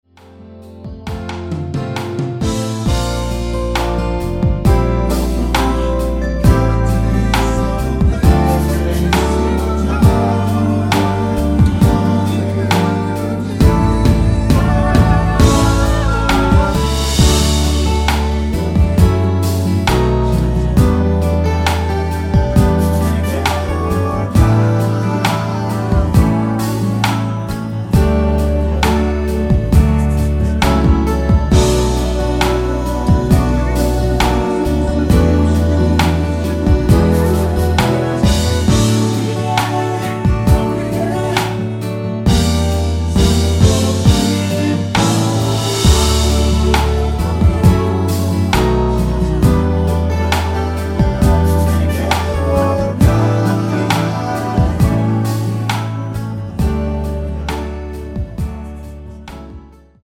원키에서(-1) 내린 코러스 포함된 MR 입니다.(미리듣기 참조)
앞부분30초, 뒷부분30초씩 편집해서 올려 드리고 있습니다.
중간에 음이 끈어지고 다시 나오는 이유는